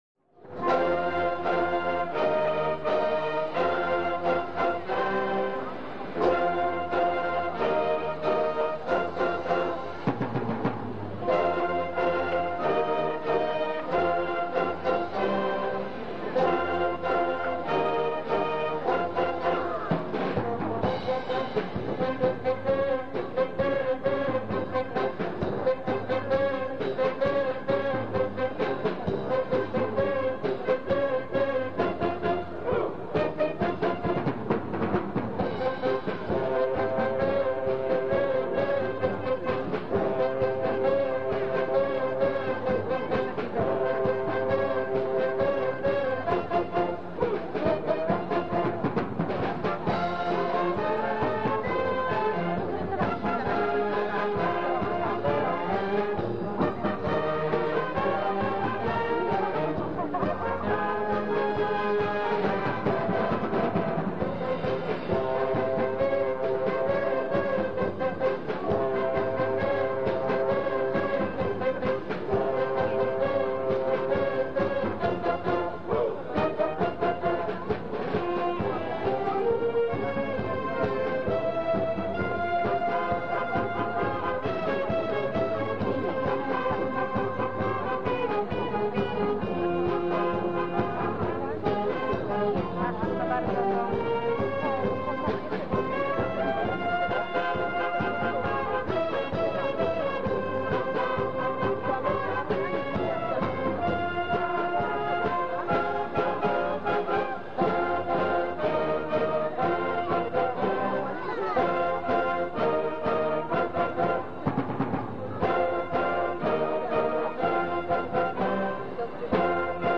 I Powiatowy Przegląd Orkiestr Dętych
Są to nagrania monofoniczne. Korzystając z prostego mikrofonu i magnetofonu, starałem się by jakość była dobra. W tle muzyki słychać publiczność oglądającą występy.
Orkiestra Dęta OSP z Murowanej Gośliny